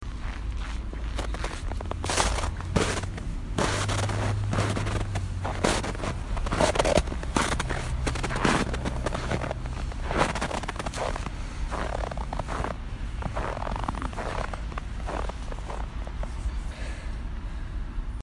酥脆的
描述：走在松脆的雪地上。
标签： 冬天 脆脆的
声道立体声